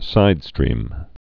(sīdstrēm)